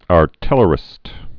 (är-tĭlər-ĭst)